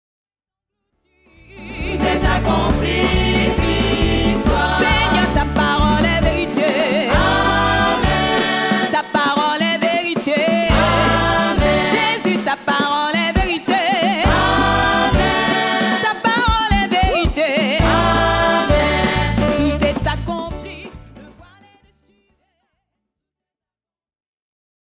Enregistrement public réalisé à Genève en 2006